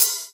• Clear Urban Open High-Hat Sound E Key 07.wav
Royality free open hat sample tuned to the E note. Loudest frequency: 9423Hz
clear-urban-open-high-hat-sound-e-key-07-VV9.wav